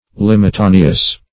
Definition of limitaneous.